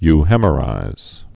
(y-hēmə-rīz, -hĕmə-)